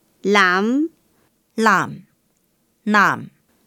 Hakka_tts